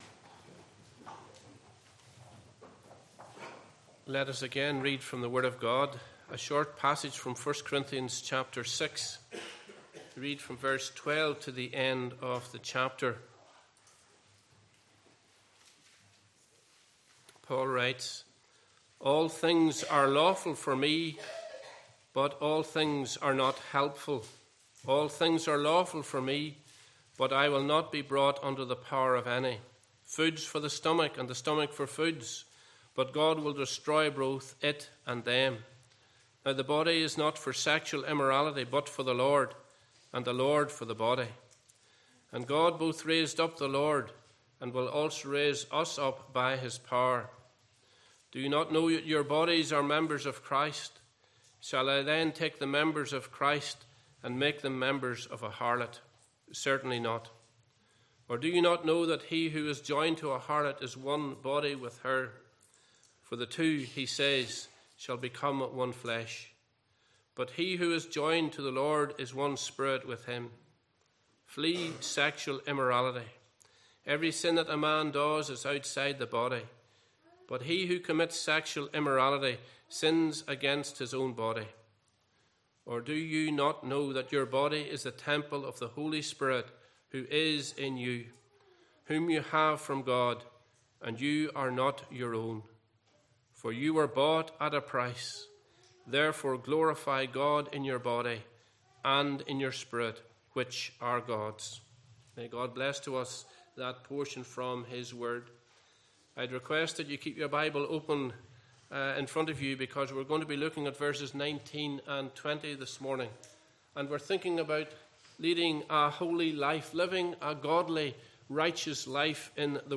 Passage: 1 Corinthians 6:12-20 Service Type: Morning Service